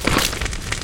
PixelPerfectionCE/assets/minecraft/sounds/mob/magmacube/small4.ogg at mc116